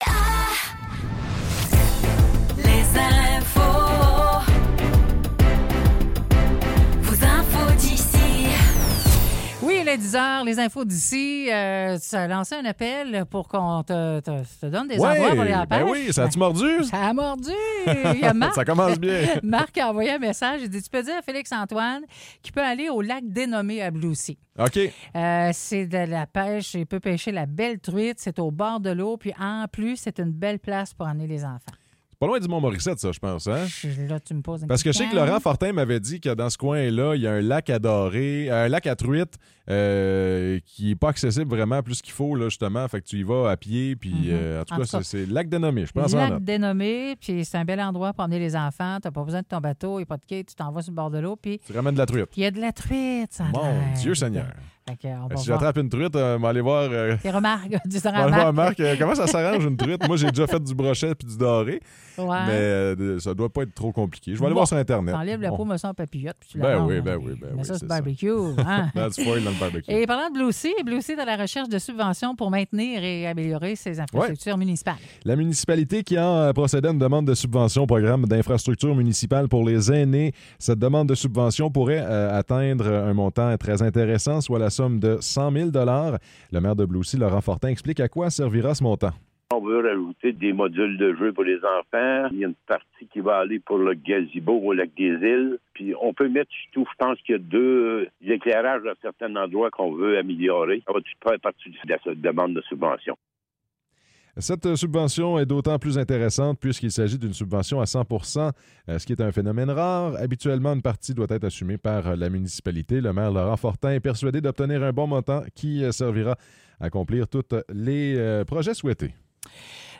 Nouvelles locales - 28 mai 2024 - 10 h